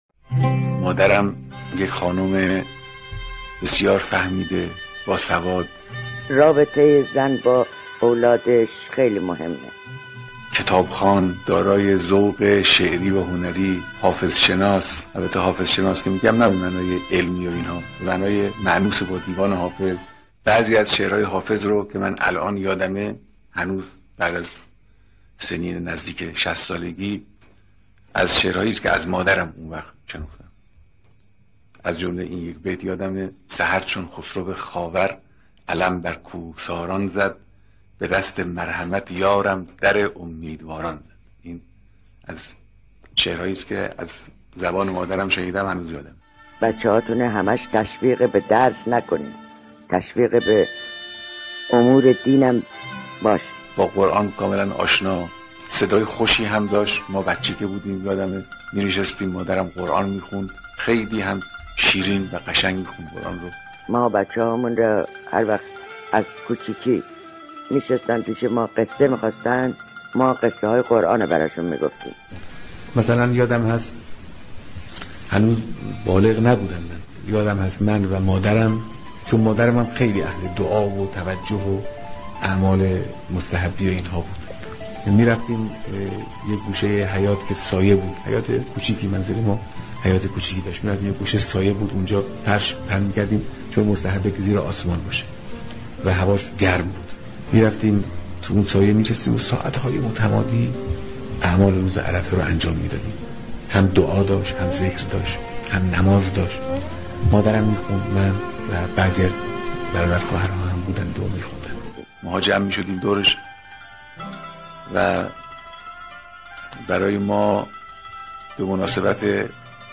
صوت | بیانات رهبری در مورد مادر